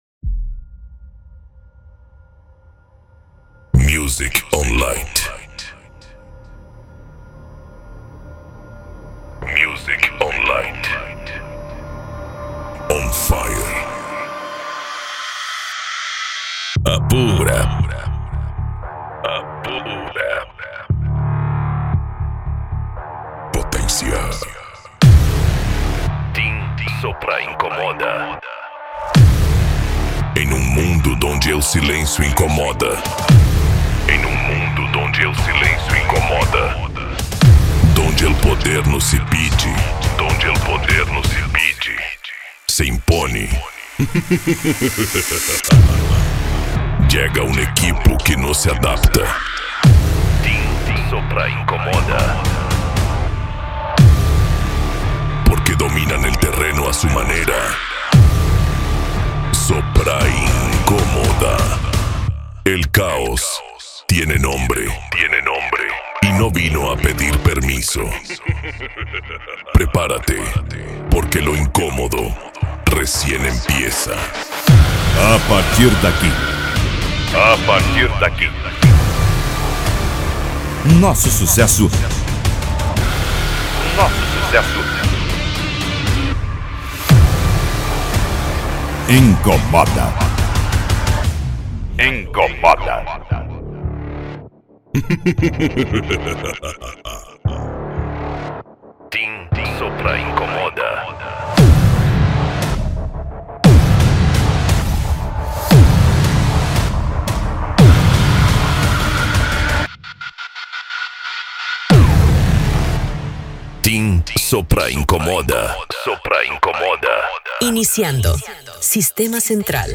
Remix
Funk
Bass